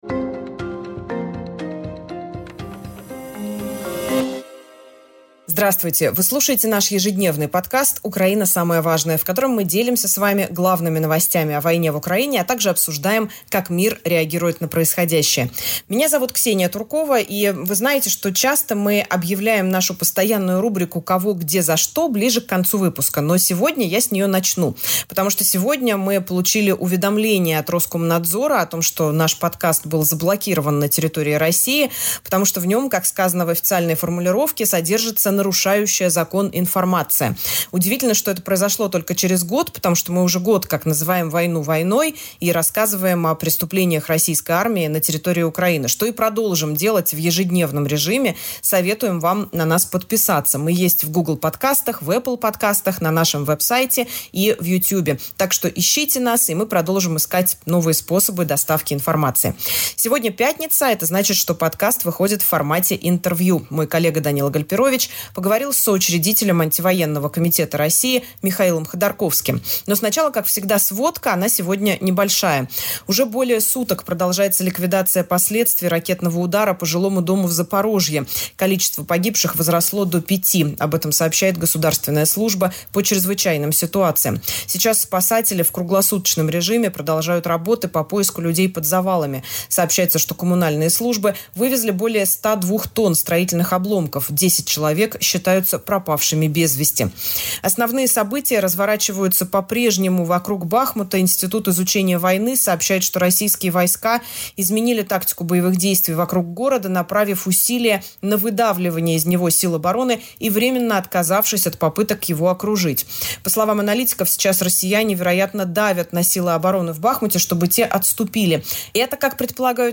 Украина. Самое важное. Интервью с Михаилом Ходорковским